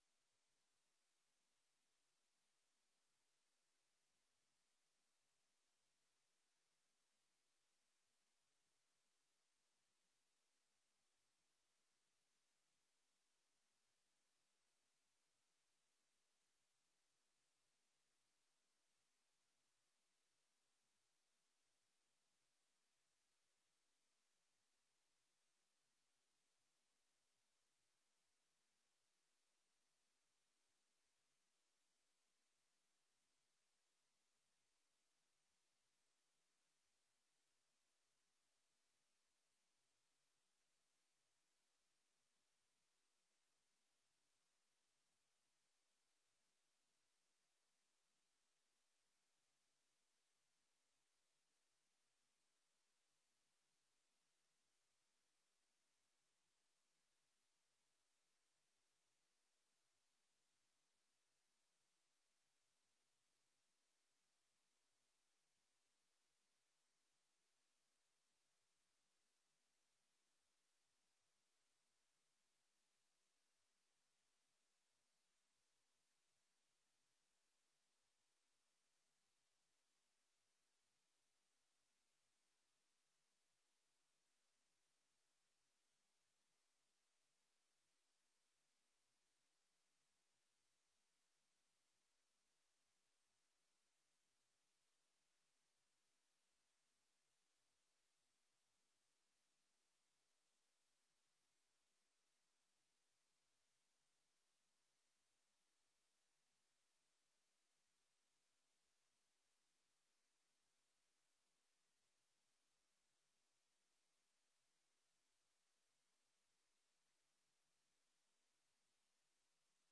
Dit is een openbare vergadering.